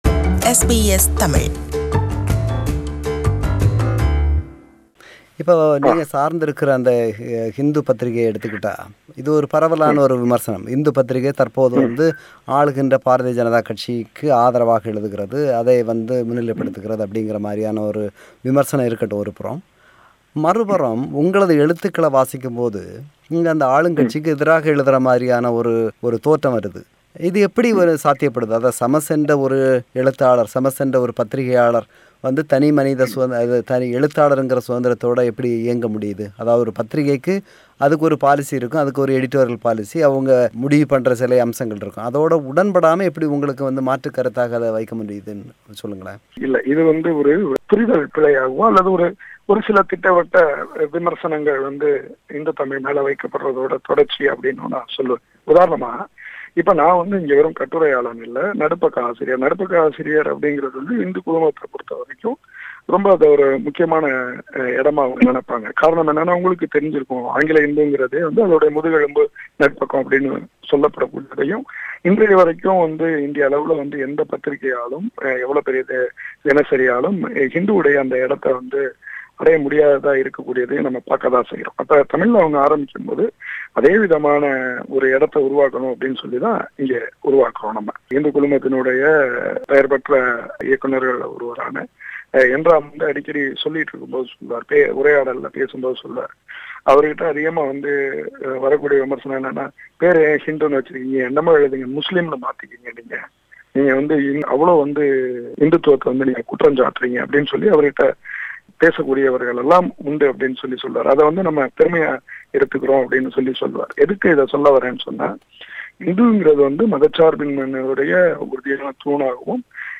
Interview – Part 2.